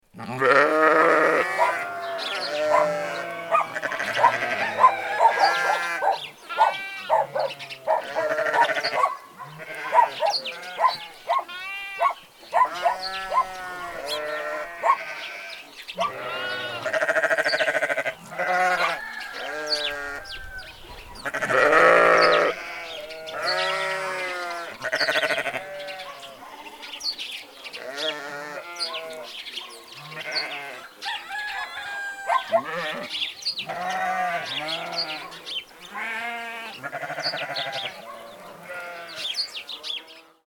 FARM ANIMAL SOUND